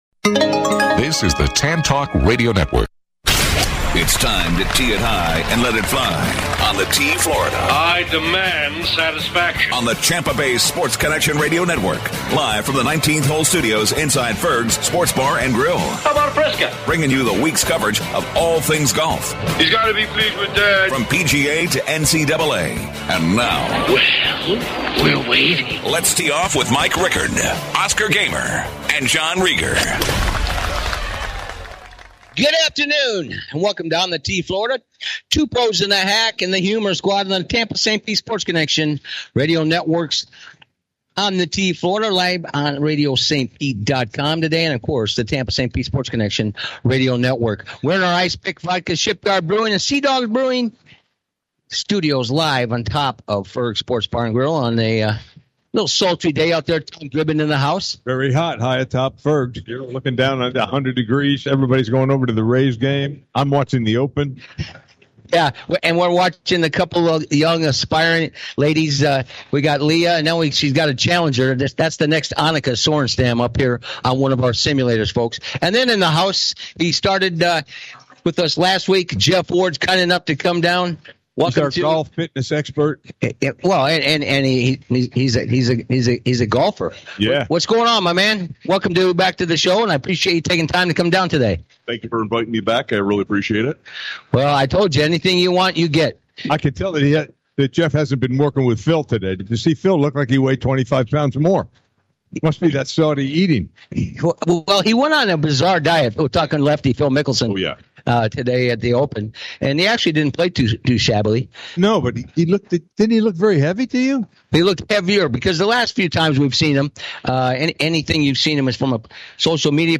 Live from Ferg's Thursdays 5-7pm ET